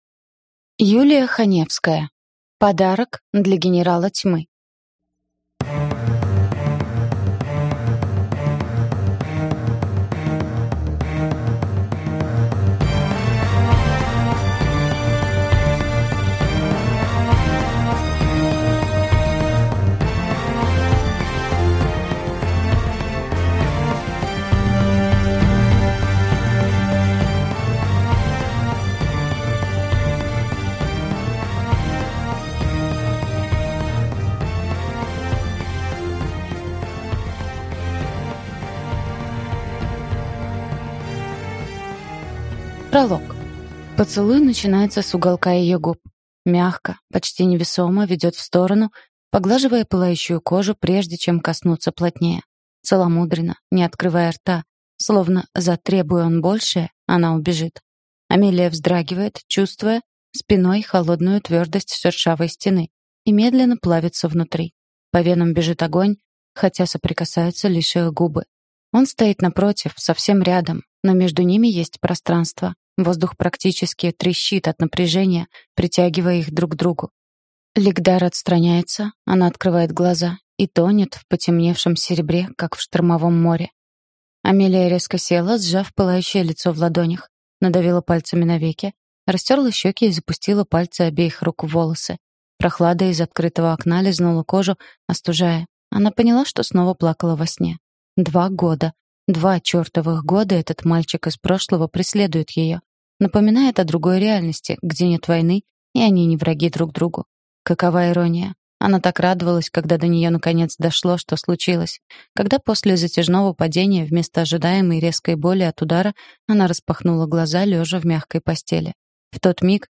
Аудиокнига Подарок для генерала Тьмы | Библиотека аудиокниг